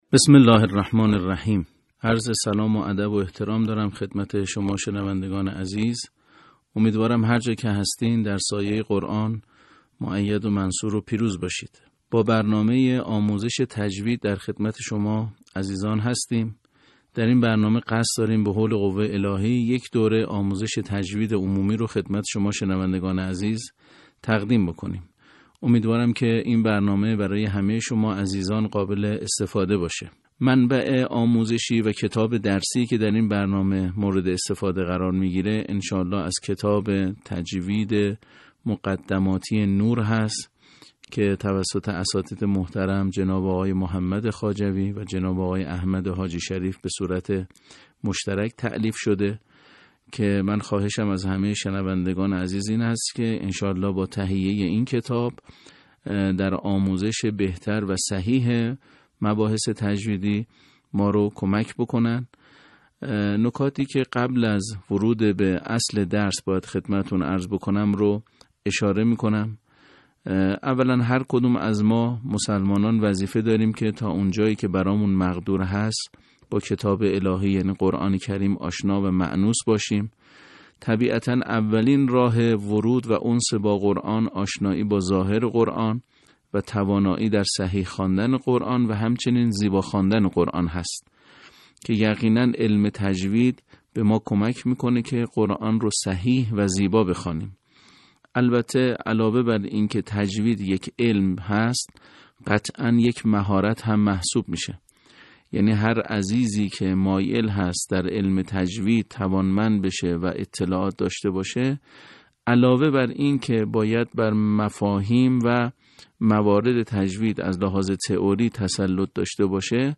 آموزش تجوید قرآن قسمت 1